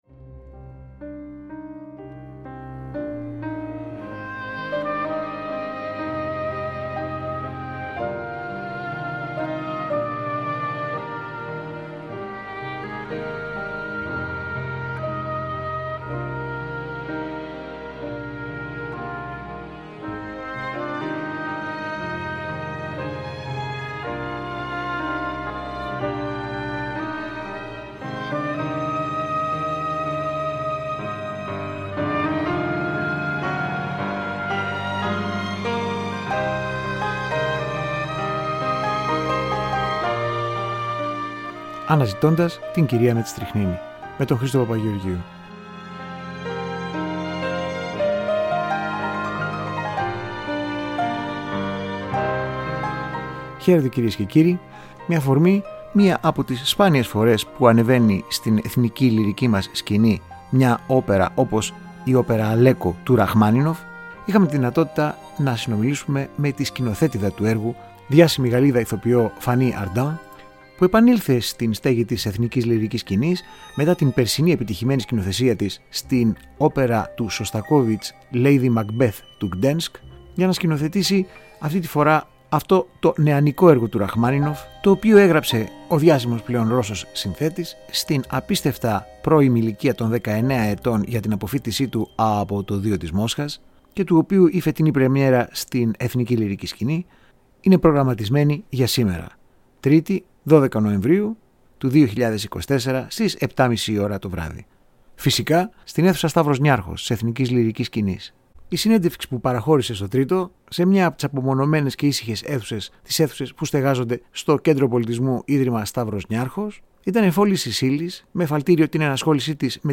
Η ασυμβίβαστη μούσα του Τρυφώ και του Αντονιόνι, η «άλλη» γαλλίδα «Κάλλας» του Τζεφιρέλι και σκηνοθέτιδα της όπερας «Αλέκο» του Σεργκέι Ραχμάνινωφ στην Εθνική Λυρική Σκηνή, Φανύ Αρντάν, σε μια οπερατική εξομολόγηση στο Τρίτο Πρόγραμμα.